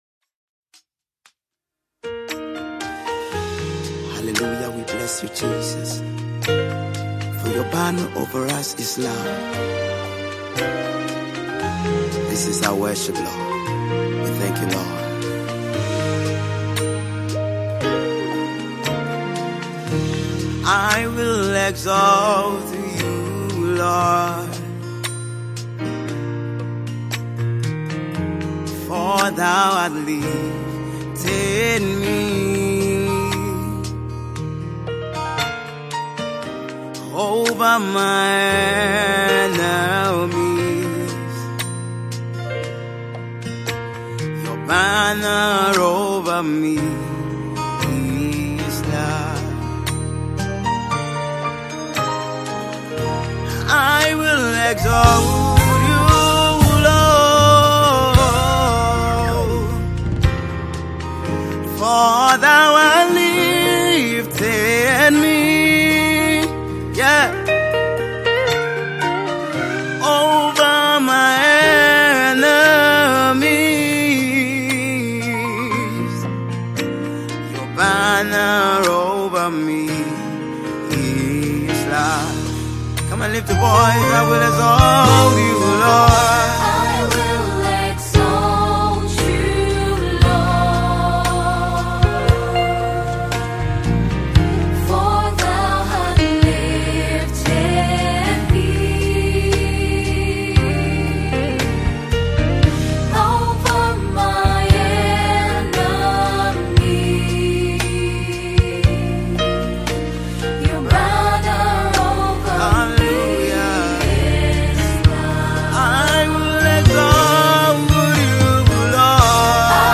Contemporary Gospel